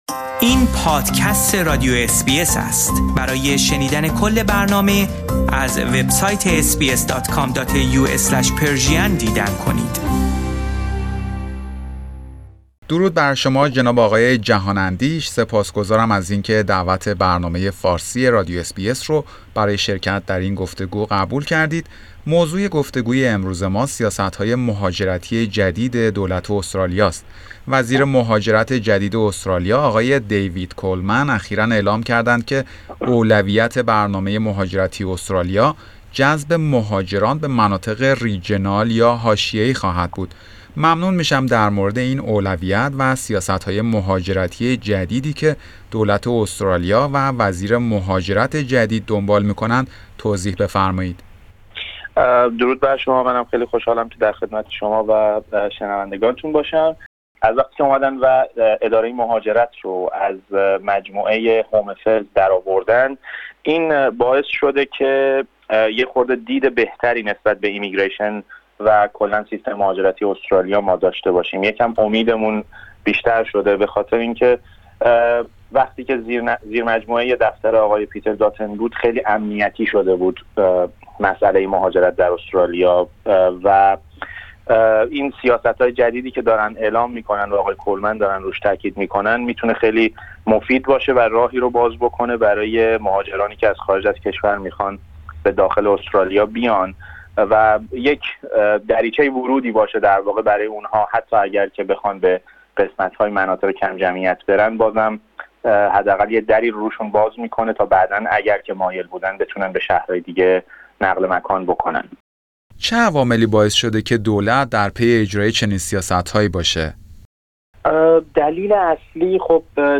در گفتگو با رادیو اس بی اس فارسی سخن می گوید.